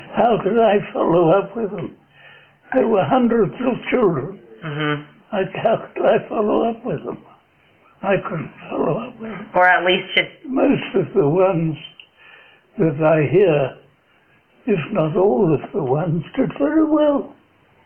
mluvil pomalu a některé otázky si musel nechat opakovat